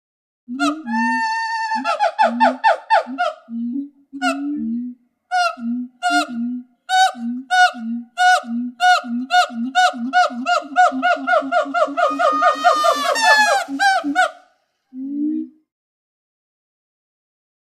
Gibbon Call. Two Gibbons Call With Low Pitched Hoots And High Yelps. Close Perspective.